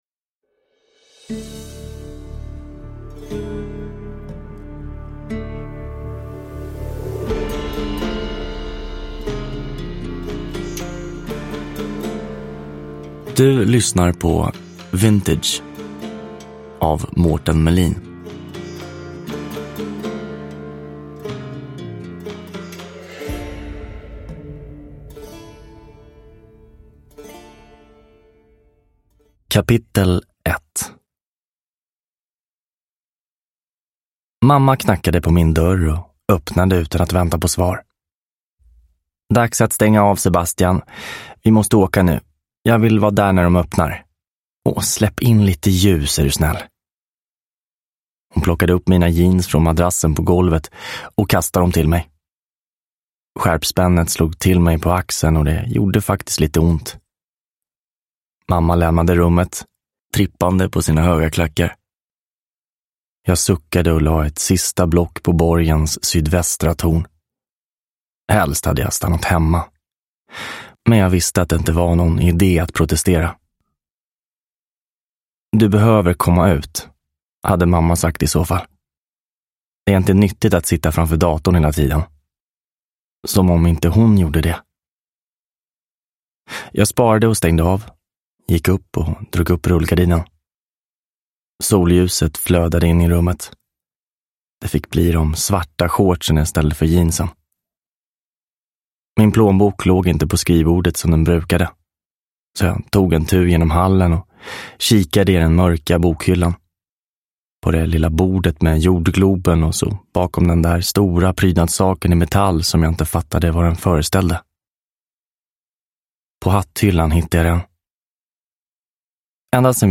Vintage – Ljudbok – Laddas ner